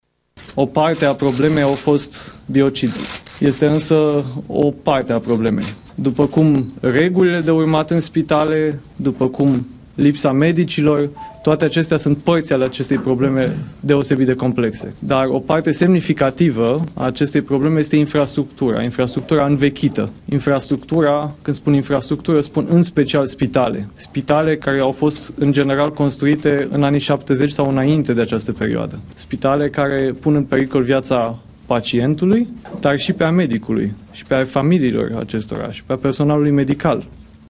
Vlad Voiculescu a făcut primele declarații într-o conferință de presă organizată la Ministerul Sănătății.